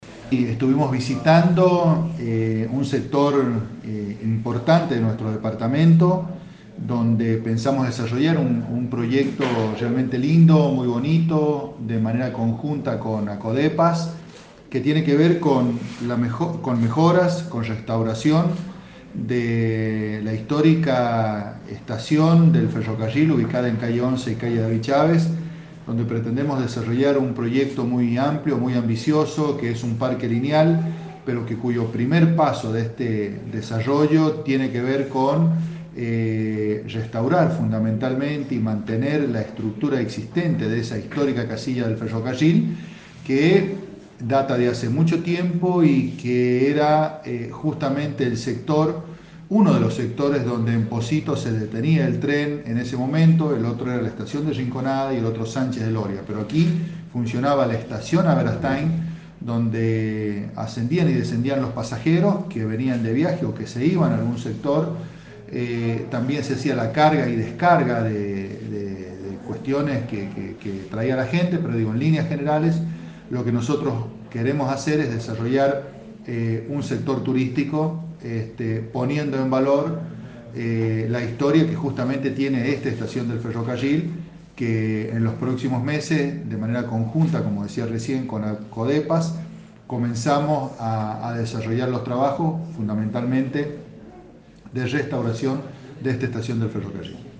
Declaraciones del Intendente Fabian Aballay sobre el «Proyecto de Estación de trenes Aberastain»
Declaraciones-Intendente-Fabian-Aballay-Proyecto-de-Estacion-de-trenes-Aberastain-online-audio-converter.com_-1.mp3